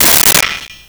Pot Lid 04
Pot Lid 04.wav